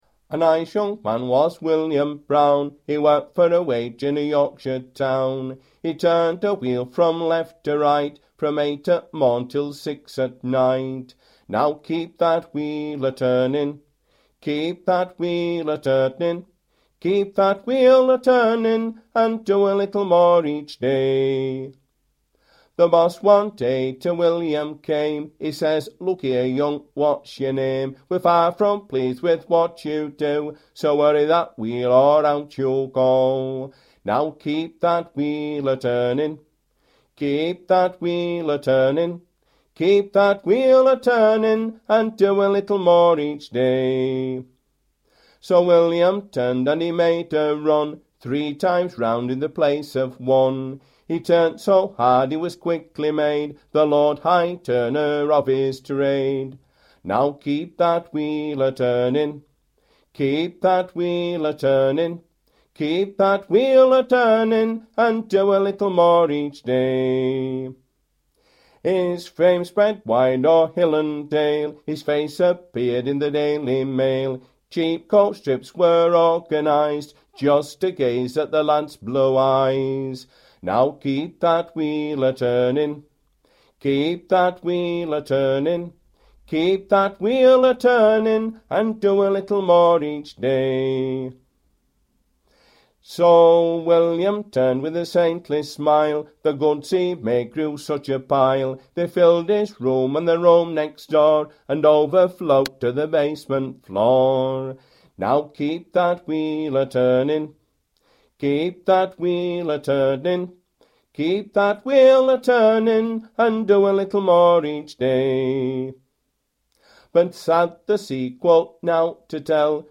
Barnsley
Industrial